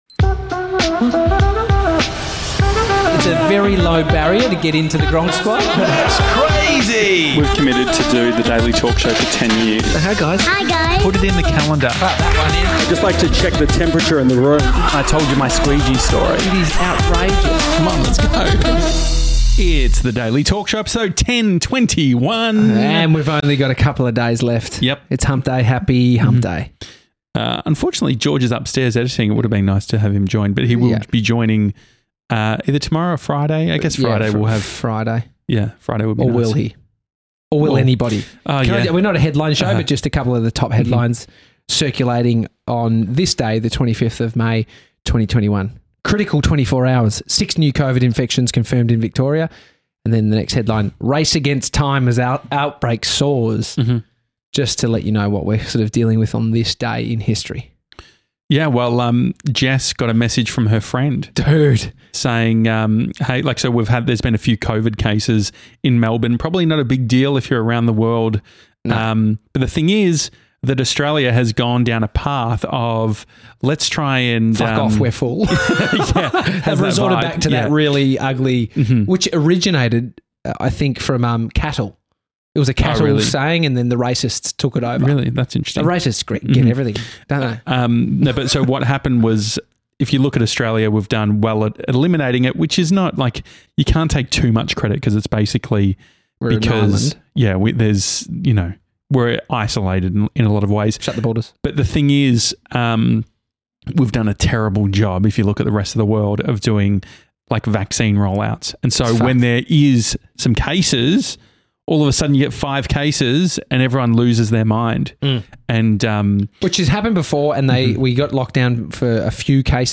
an Australian talk show and daily podcast
Regularly visited by guests and gronks!